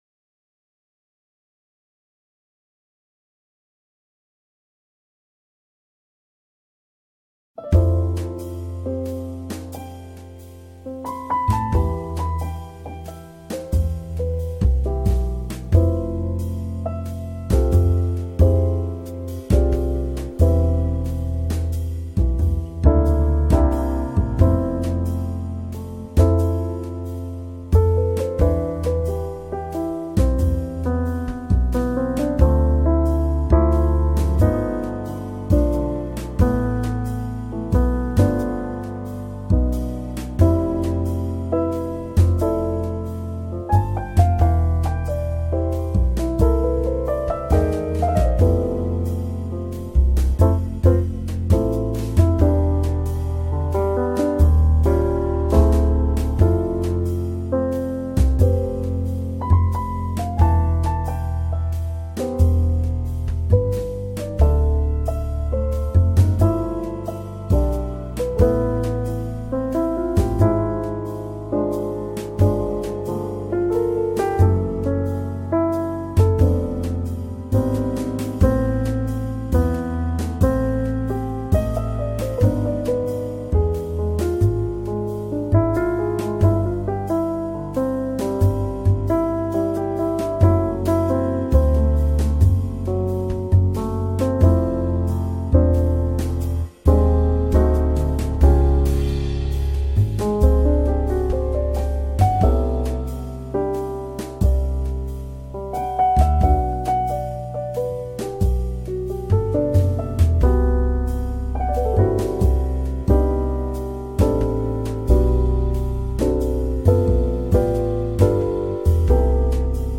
Dreamy Soundscapes: Soothing Water and Nature Sounds to Aid in Relaxation and Sleep.
All advertisements are thoughtfully placed only at the beginning of each episode, ensuring you enjoy the complete ambient sounds journey without any interruptions.